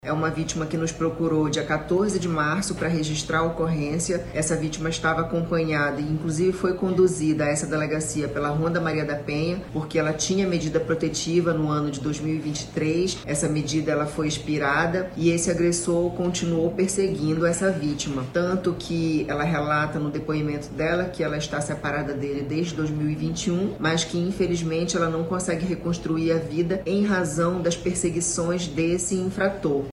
SONORA-1-PRESO-PERSEGUICAO-MULHER-.mp3